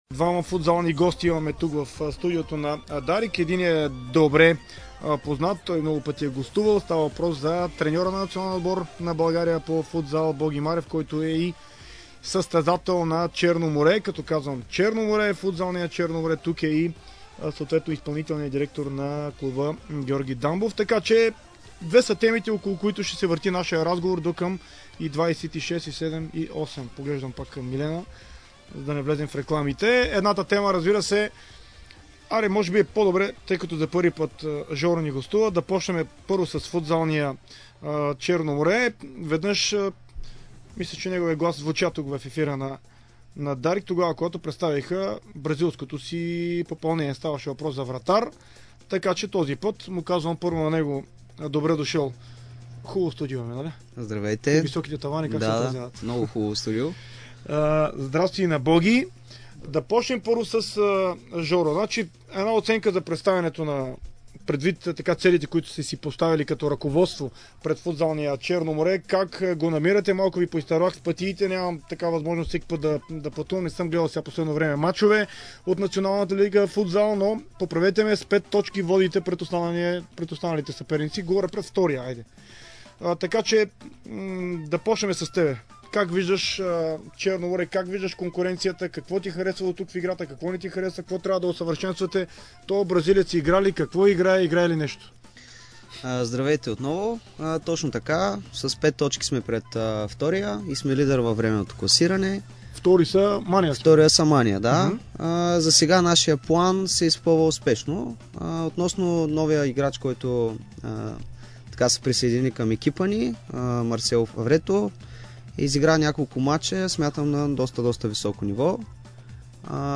гостува в спортното шоу на Дарик Варна